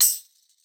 SouthSide Hi-Hat (15).wav